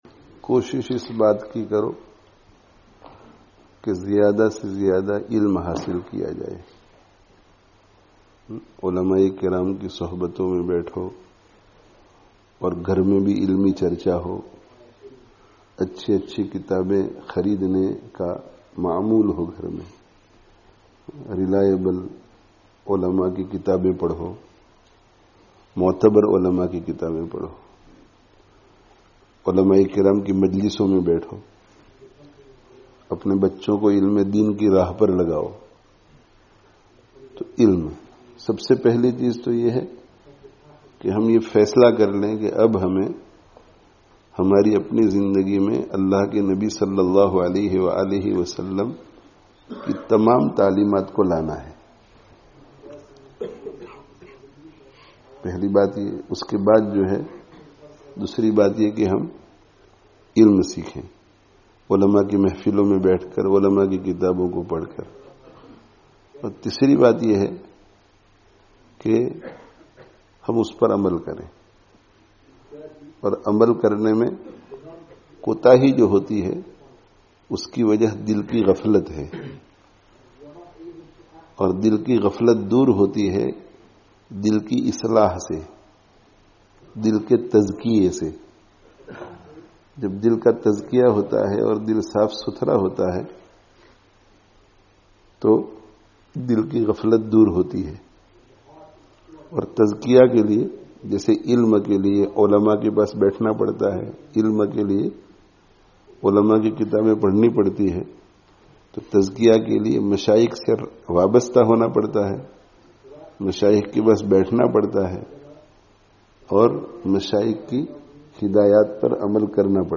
[Informal Majlis] 'Ilm awr Islāh kī Fikr Karo (22/11/19)